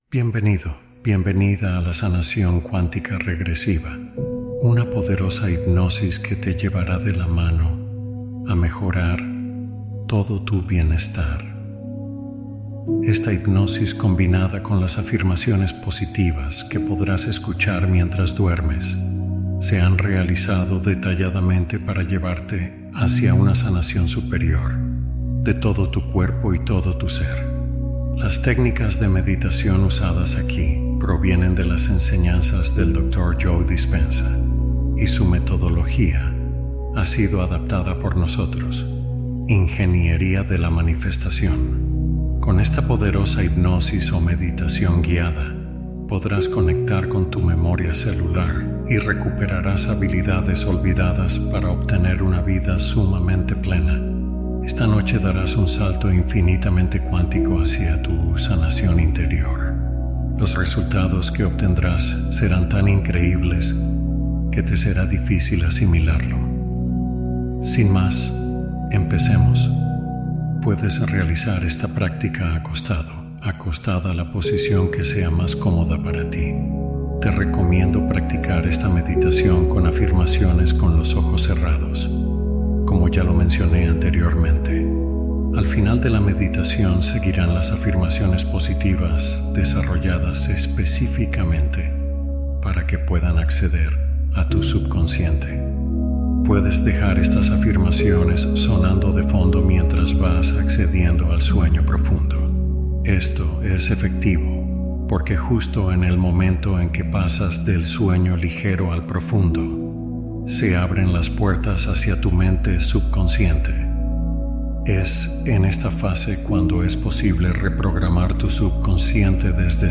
Exploración regresiva simbólica: hipnosis profunda nocturna